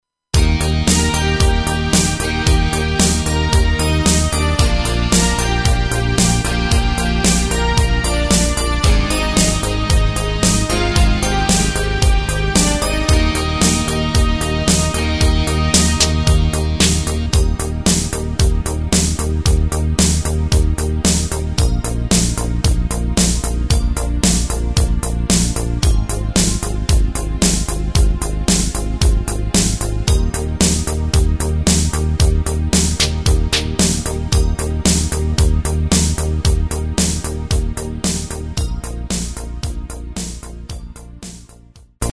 Takt:          4/4
Tempo:         113.00
Tonart:            Eb
POP aus dem Jahr 2024!
Playback mp3 Mit Drums